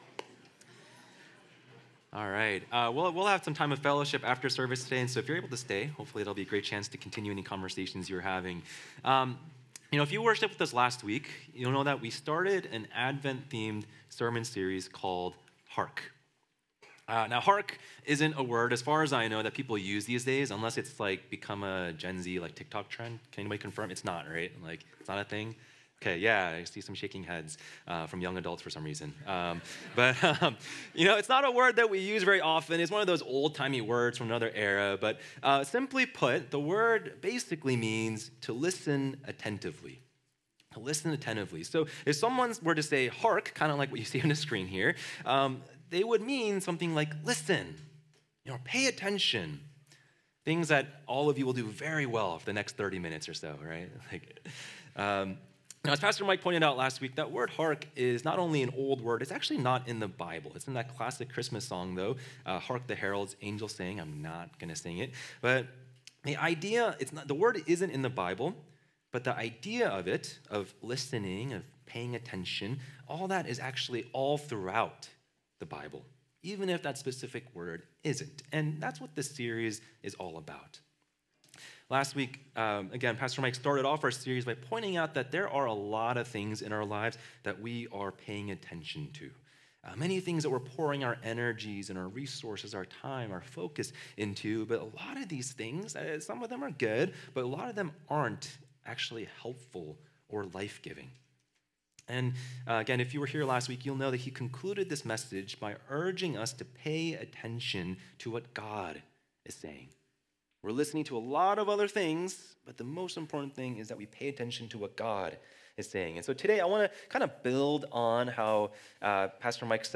2023 Current Sermon Good News of Great Joy Hark!